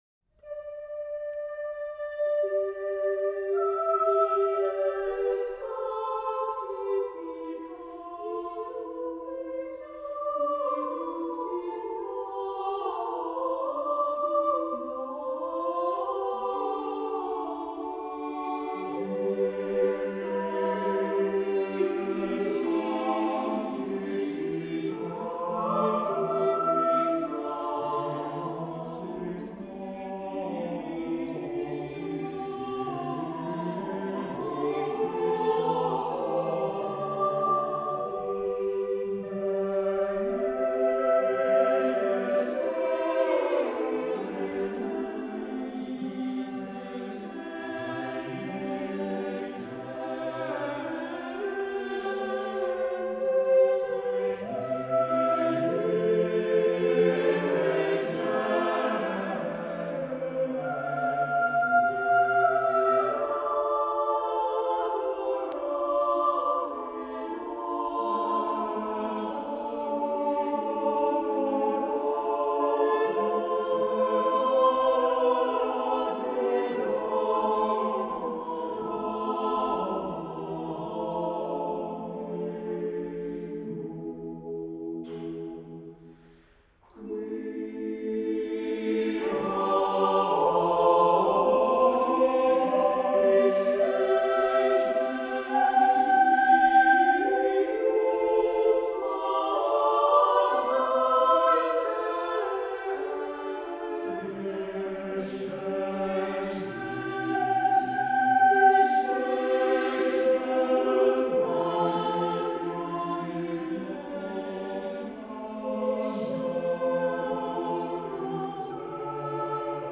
choeur de chambre